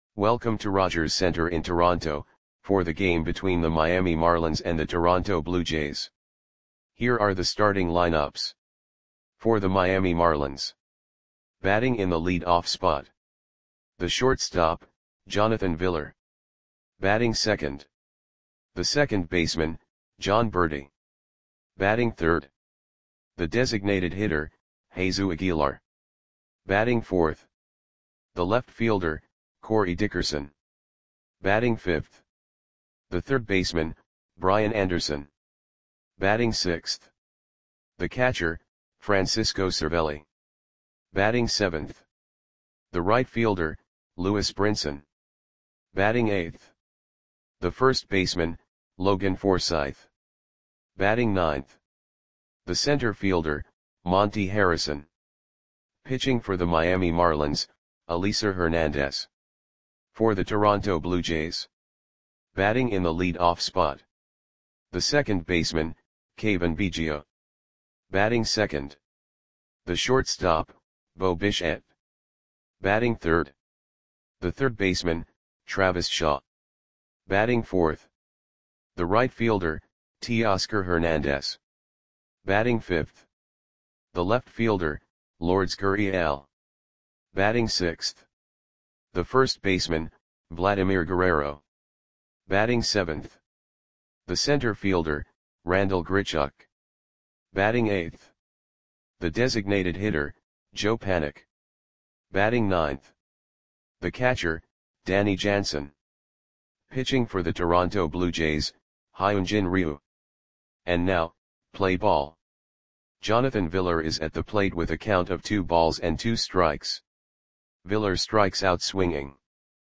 Audio Play-by-Play for Toronto Blue Jays on August 11, 2020
Click the button below to listen to the audio play-by-play.